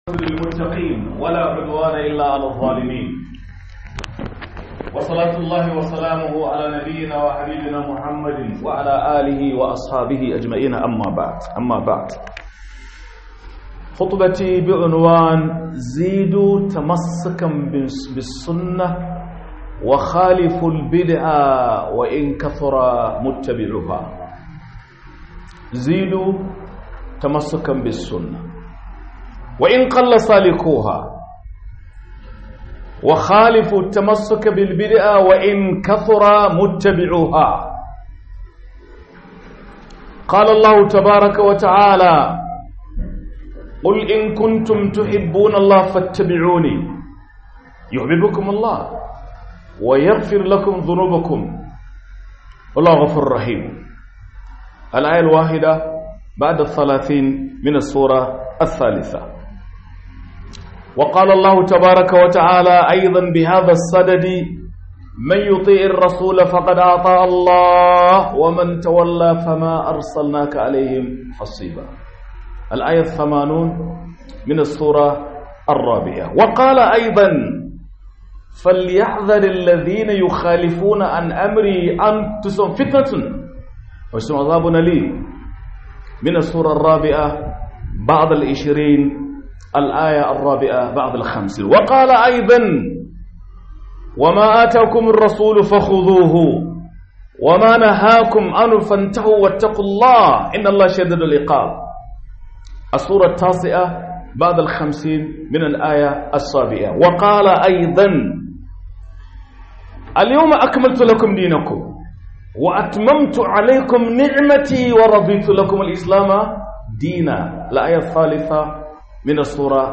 KU KULA DA SUNNAH KU GUJI BIDI'AH - HUDUBA